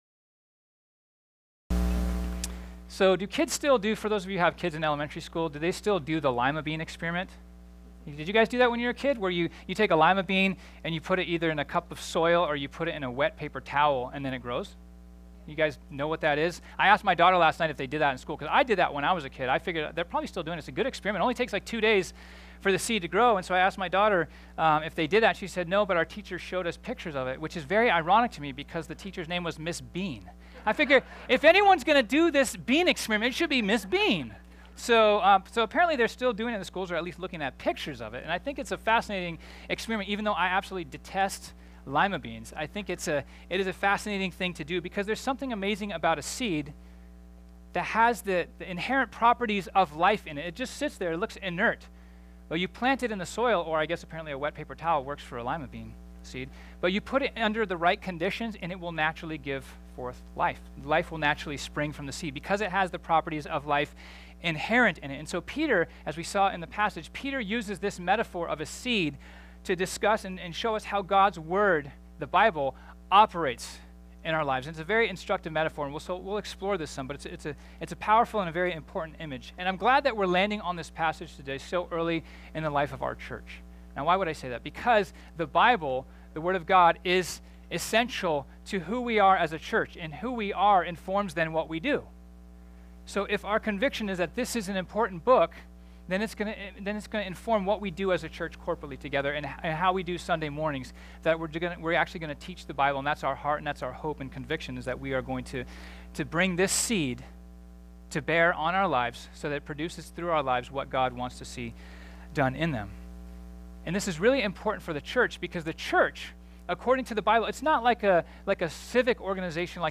This sermon was originally preached on Sunday, February 25, 2018.